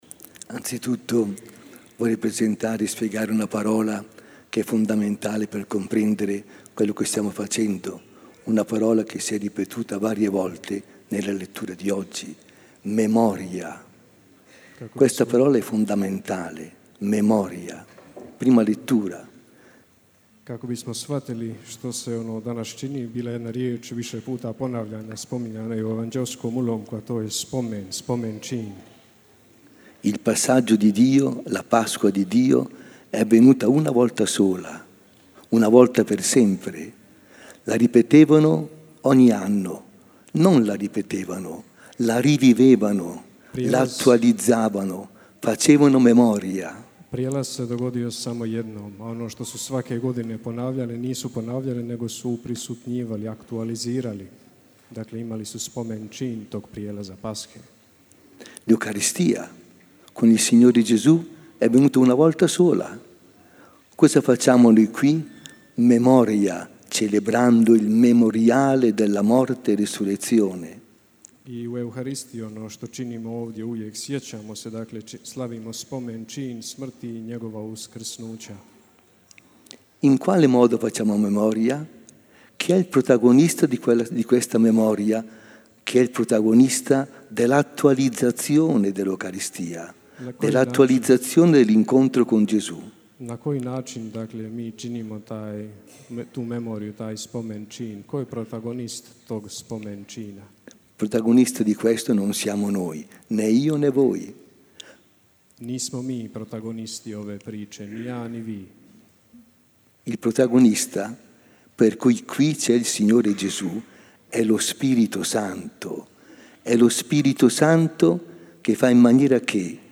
Misu Večere Gospodnje na Veliki četvrtak, 18. travnja, u crkvi svetog Jakova u Međugorju predvodio je apostolski vizitator s posebnom ulogom za župu Međugorje nadbiskup Aldo Cavalli uz koncelebraciju brojnih svećenika.